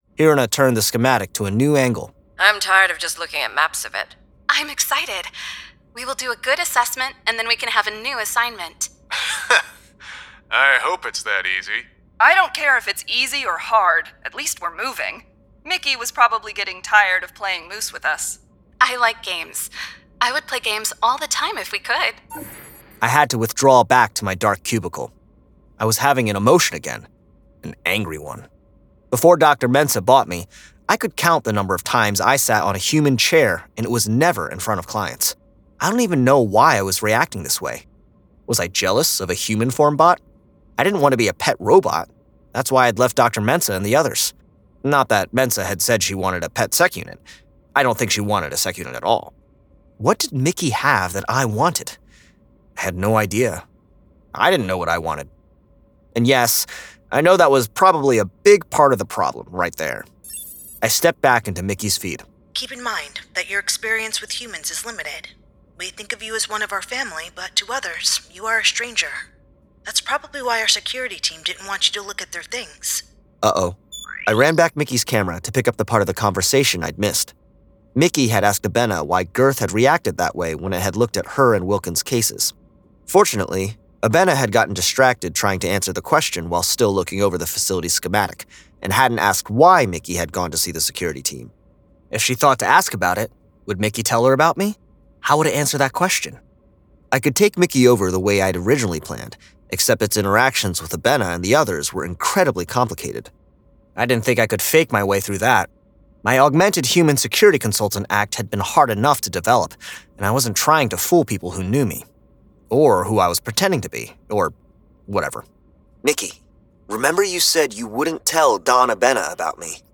Full Cast. Cinematic Music. Sound Effects.
Genre: Science Fiction
Adapted from the novel and produced with a full cast of actors, immersive sound effects and cinematic music!